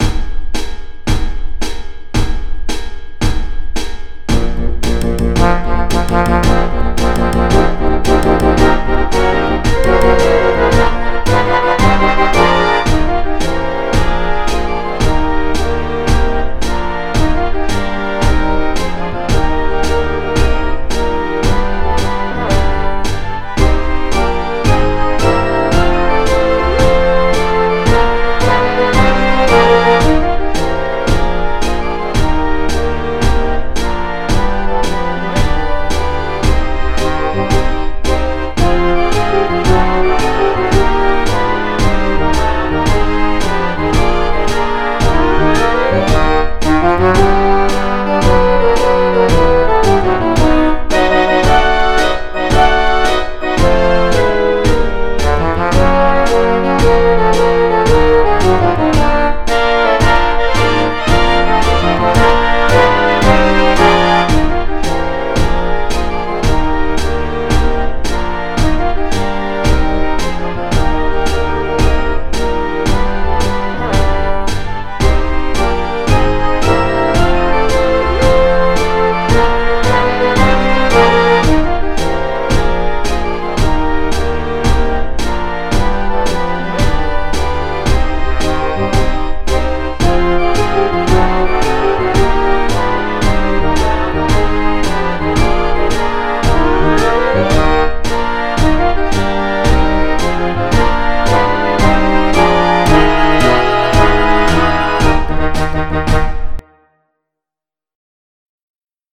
You can listen here while recording yourself playing (8 beats click intro)
Semper-Supra-CB2-w-CLICKS.mp3